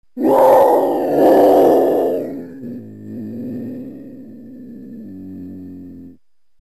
ghul-schrei.mp3